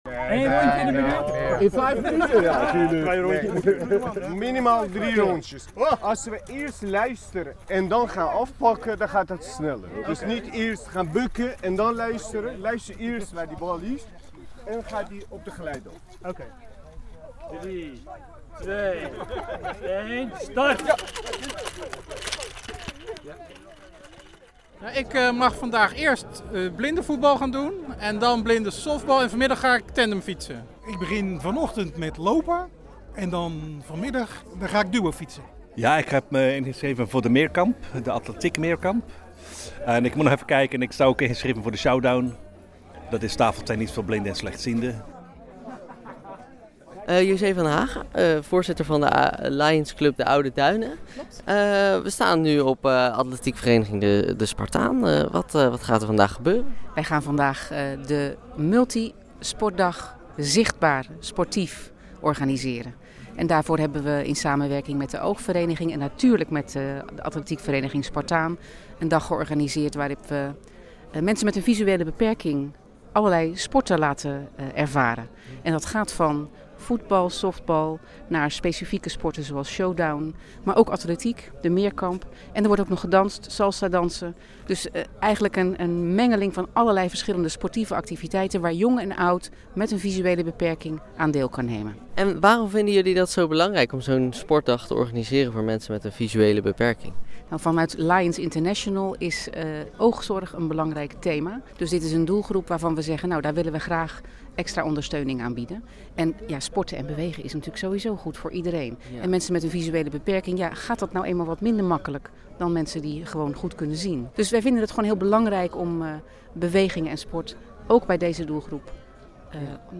U hoort eerst een groep voetballers die de bal aan elkaar doorgeeft.